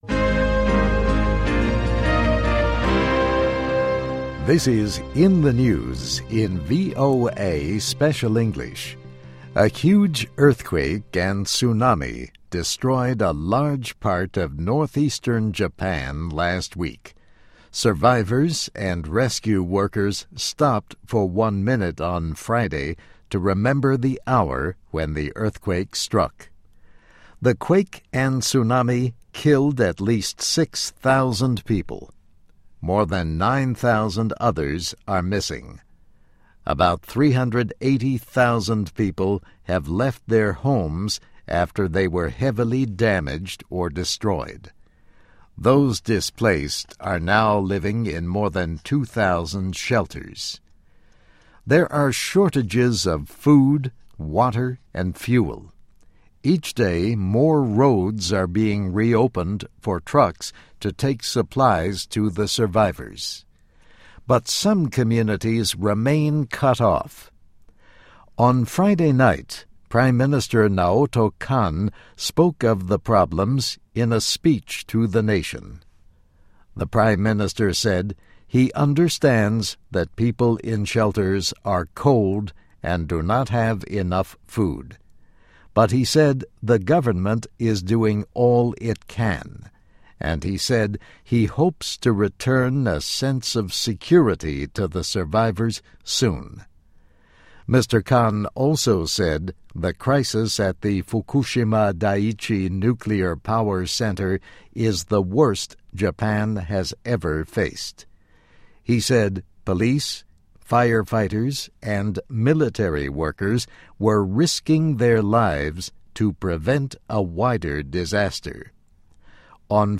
VOA慢速英语, In the News, 日本继续致力于处理福岛核危机